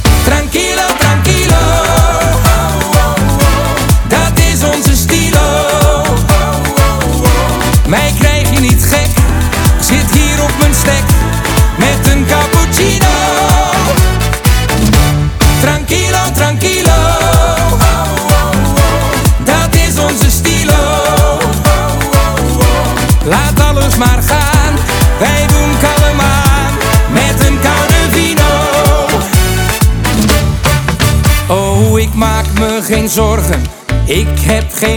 2025-06-06 Жанр: Поп музыка Длительность